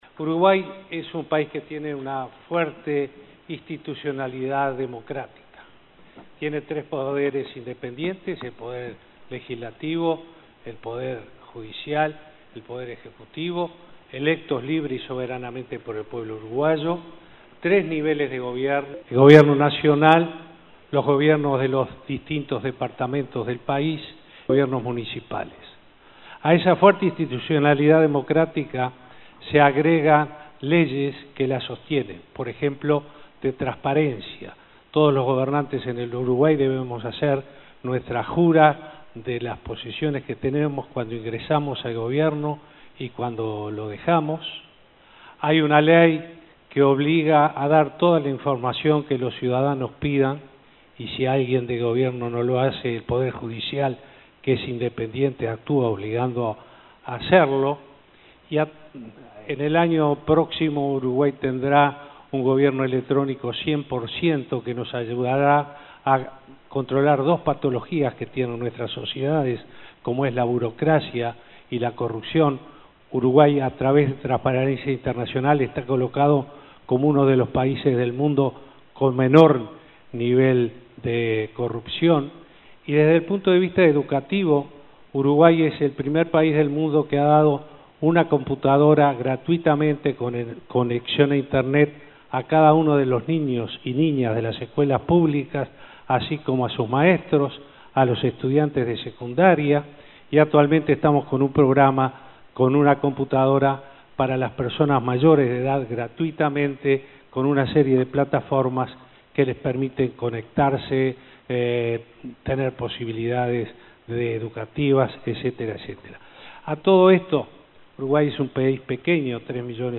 En su intervención en la apertura de la IV cumbre de la Alianza para el Gobierno Abierto (OGP, por sus siglas en inglés), insistió en que su Ejecutivo es uno "de cercanía", y el país, "uno de los que tiene menor nivel de corrupción".